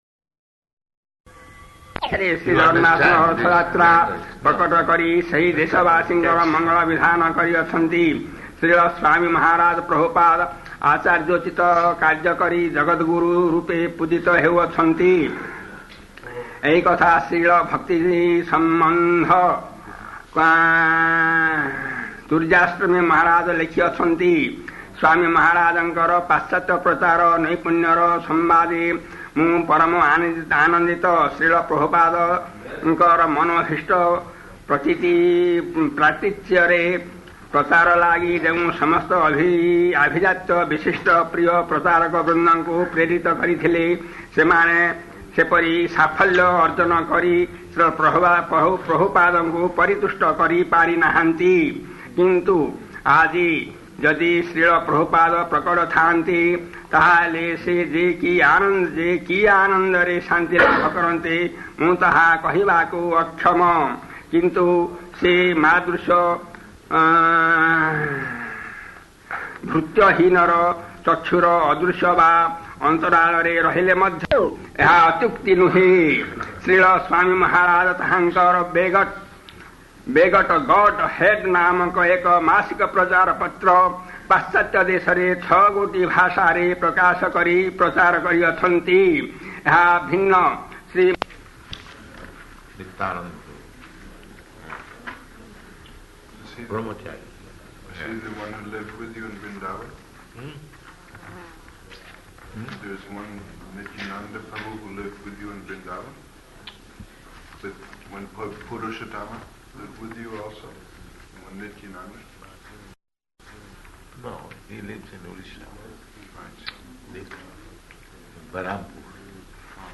Room Conversation
Room Conversation --:-- --:-- Type: Conversation Dated: February 8th 1974 Location: Vṛndāvana Audio file: 740208R1.VRN.mp3 [poor recording] [Indian devotee reciting Sanskrit praṇaṁ-mantras ] [break] [01:34] Prabhupāda: Nītyānanda Prabhu.